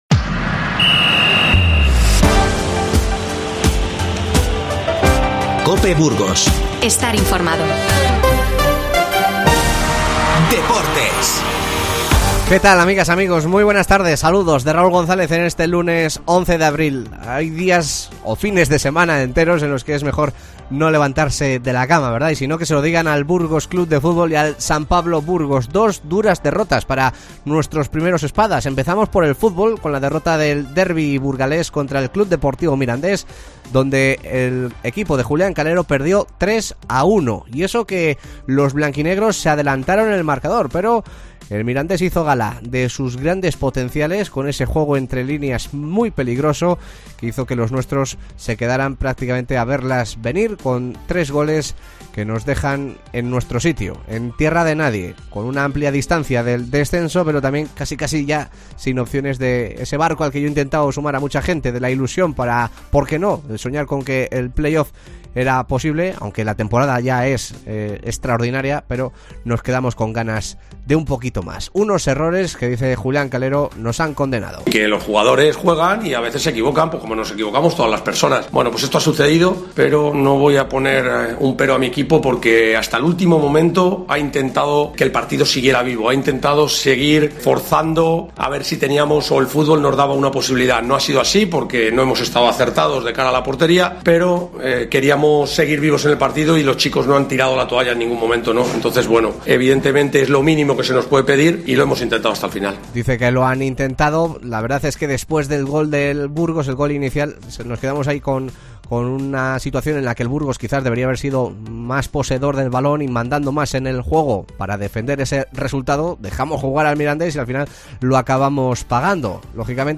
Escucha a los protagonistas del Burgos CF y del San Pablo Burgos tras sus respectivas derrotas ante Mirandés y Baskonia.